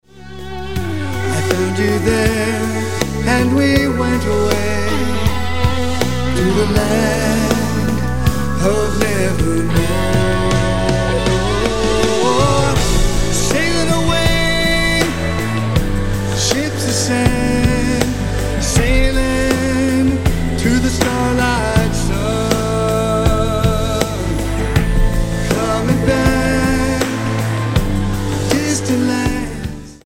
Powerful rock anthem
powerful tenor voice
guitar